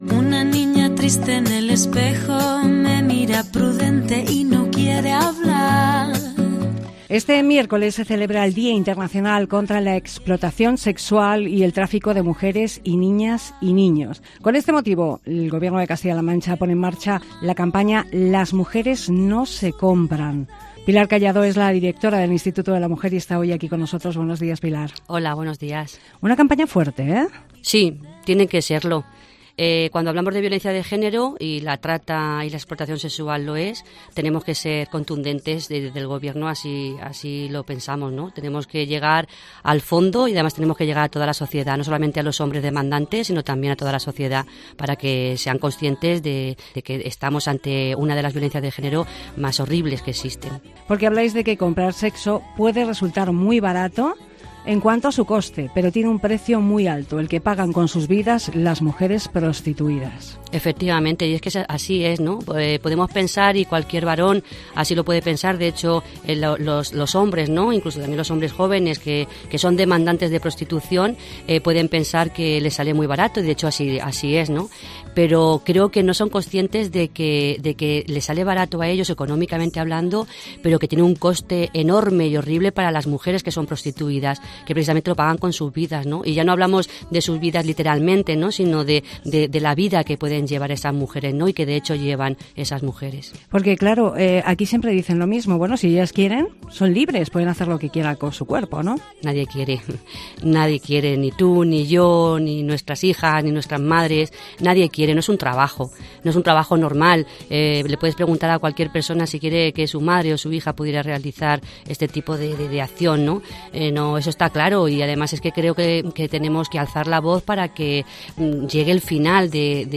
Entrevista a Pilar Callado, Directora del Instituto de la Mujer